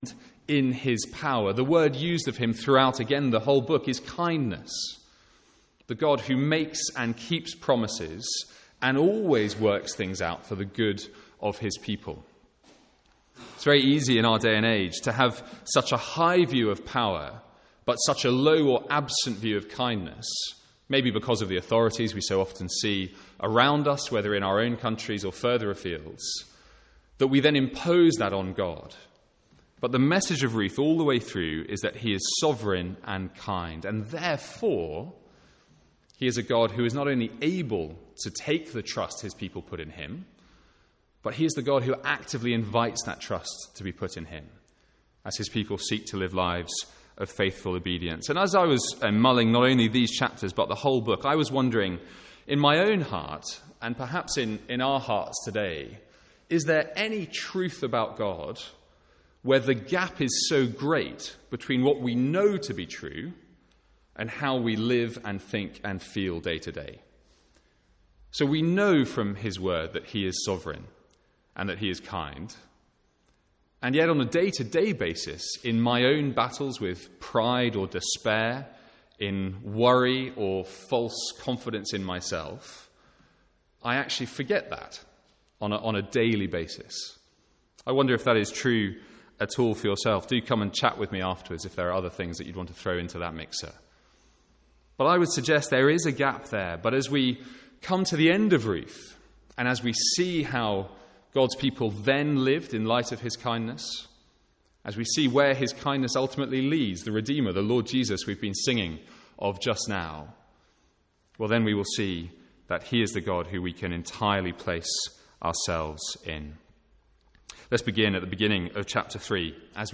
Sermons | St Andrews Free Church
From the Sunday morning series in Ruth. (First minute missing).